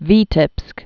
(vētĭpsk)